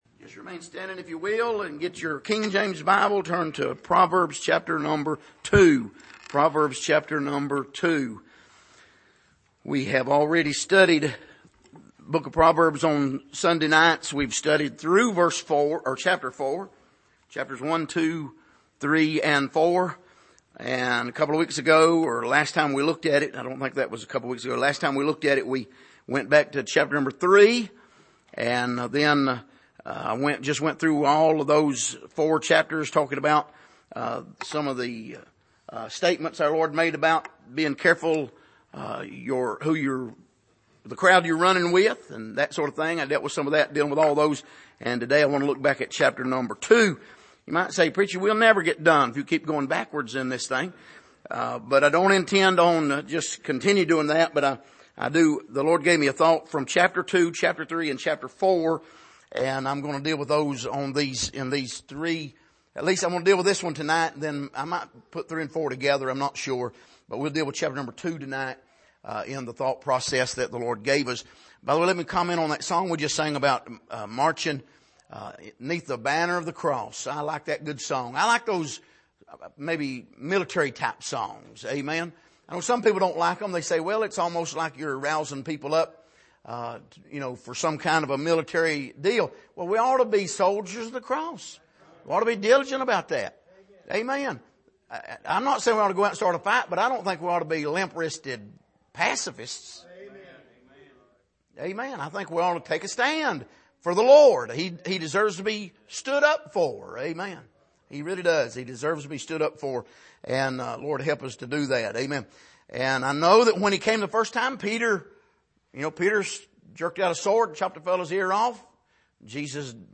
Passage: Proverbs 2:6-9 Service: Sunday Evening